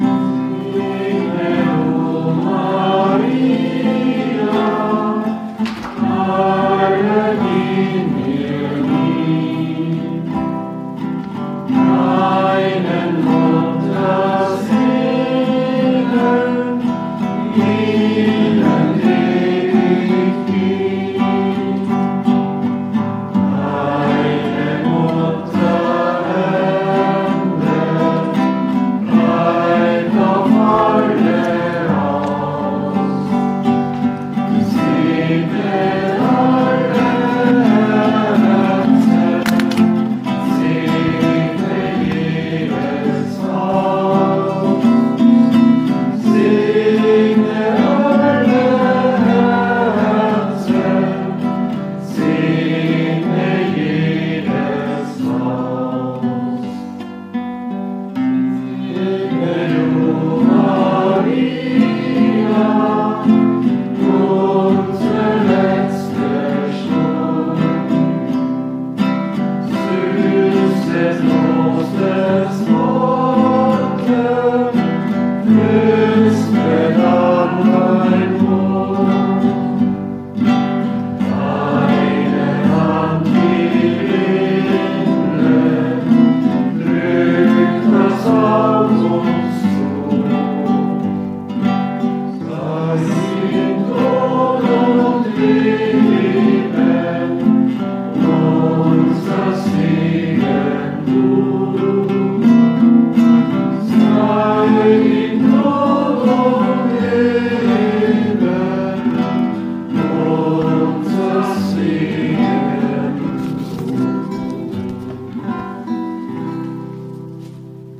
Am Nachmittag bildete die gemeinsame Messe im Pfarrheim den Höhepunkt.